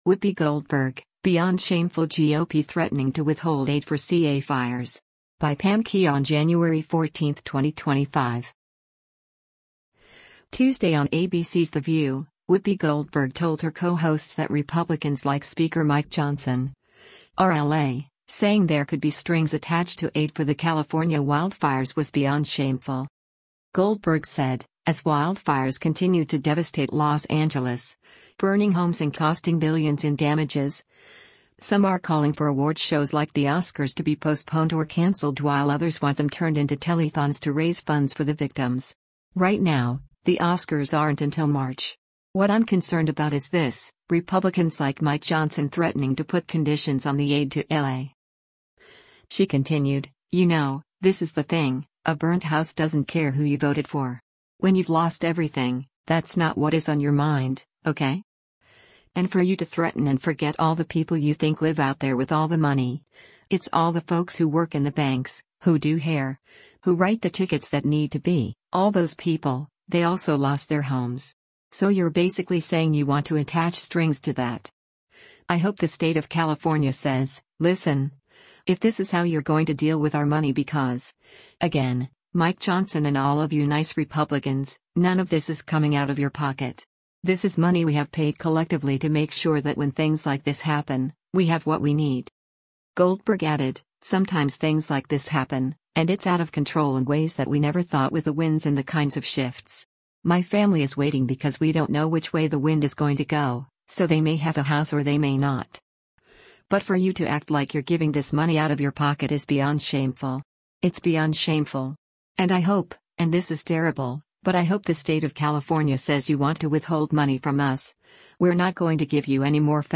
Tuesday on ABC’s “The View,” Whoopi Goldberg told her co-hosts that Republicans like Speaker Mike Johnson (R-LA) saying there could be strings attached to aid for the California wildfires was “beyond shameful.”